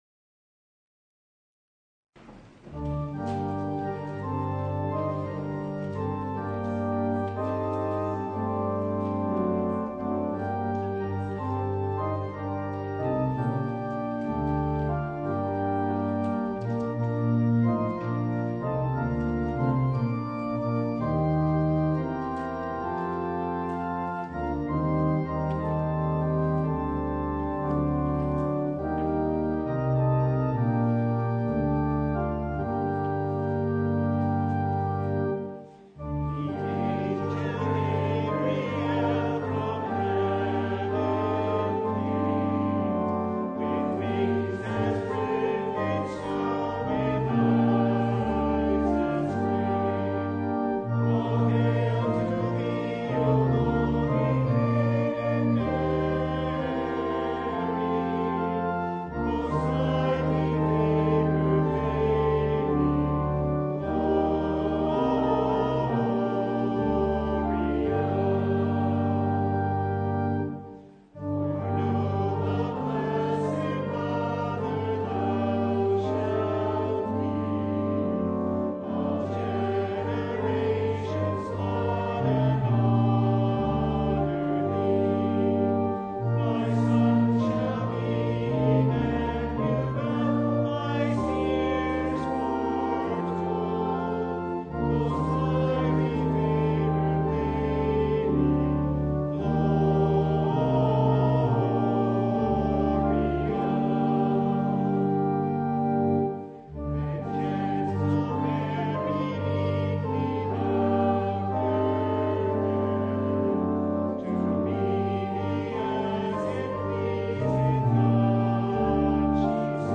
Service Type: Lent Midweek Noon
Full Service